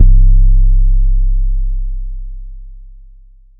OZ (808).wav